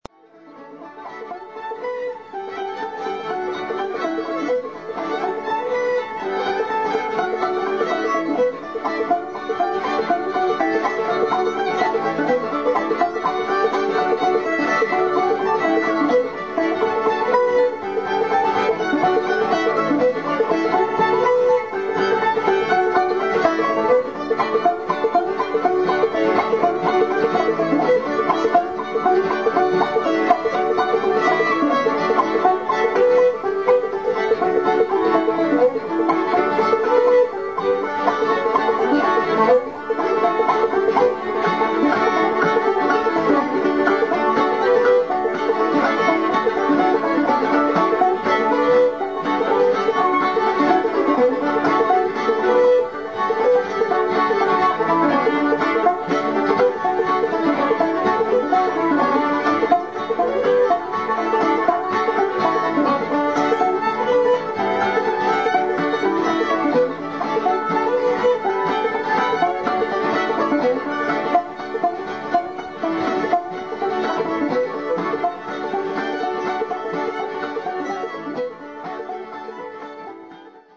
Fiddle
Banjo